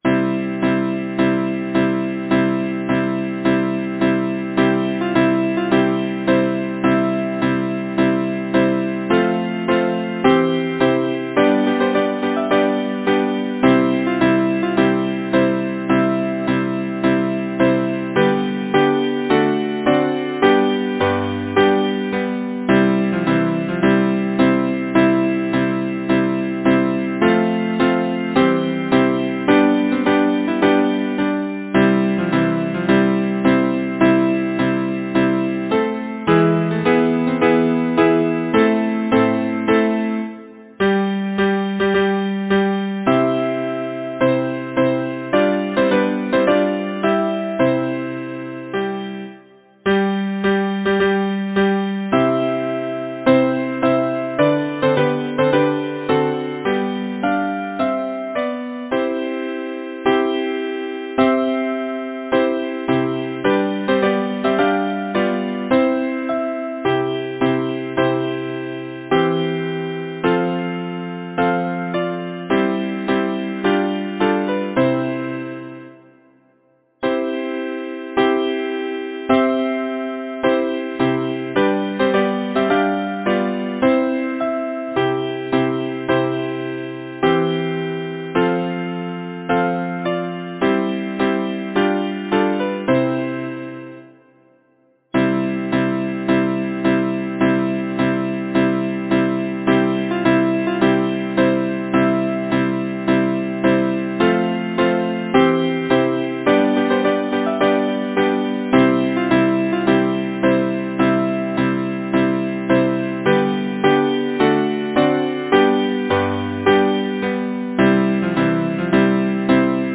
Title: Flora and Forester Composer: John H. Hewitt Lyricist: Number of voices: 4vv Voicing: SATB Genre: Secular, Partsong
Language: English Instruments: A cappella